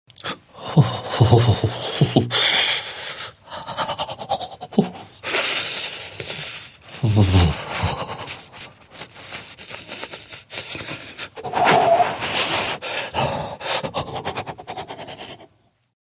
Звуки дрожания